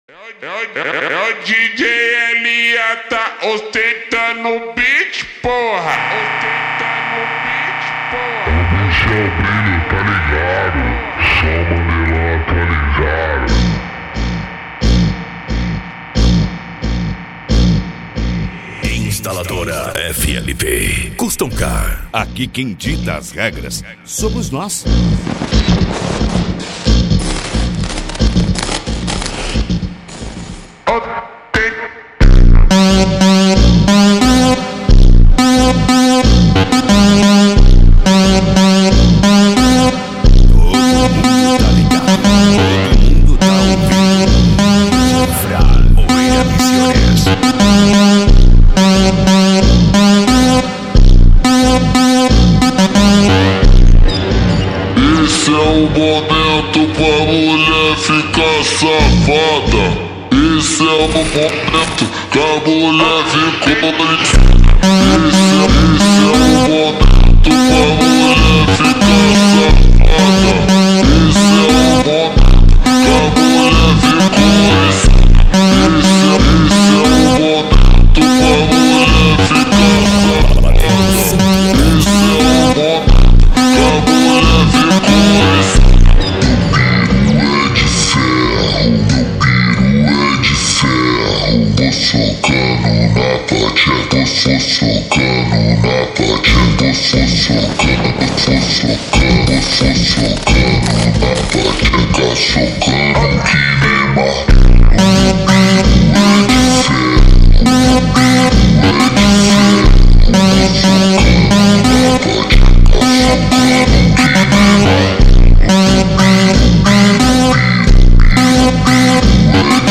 Bass
Cumbia
Electro House
PANCADÃO
Psy Trance
Remix